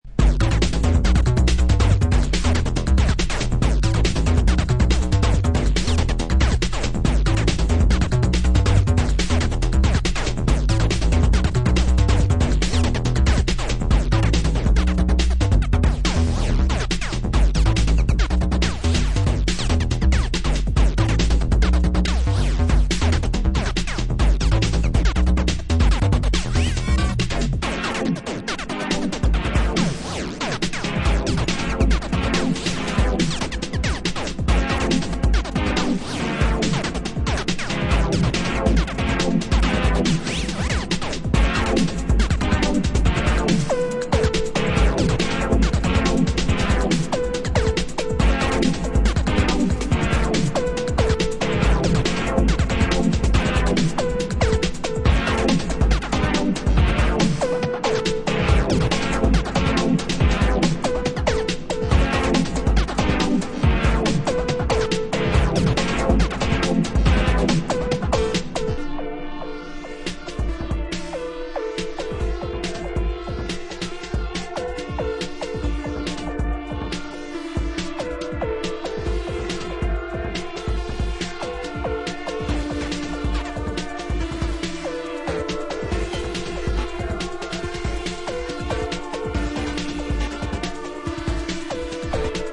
New York Disco, Chicago House and Detroit Techno
Electro Electronix Acid